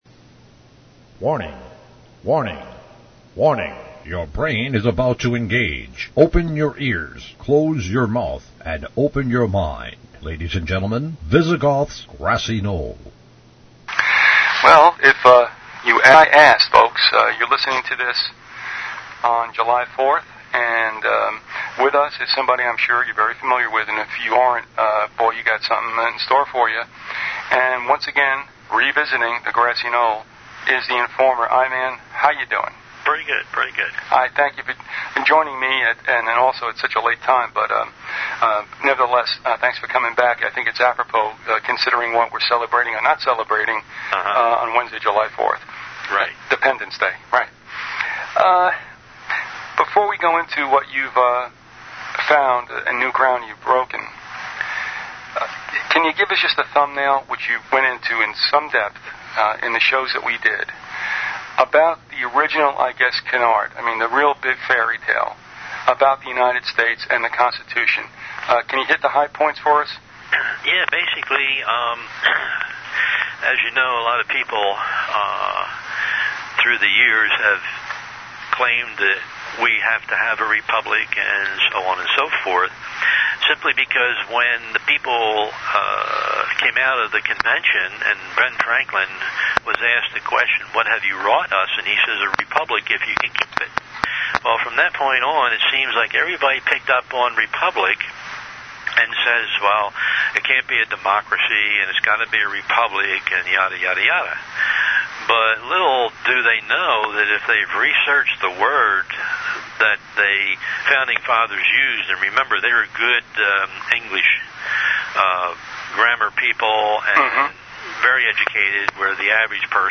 If you took notes and would like to share them with others, please send an email to the archivist and be sure to reference the title of the interview.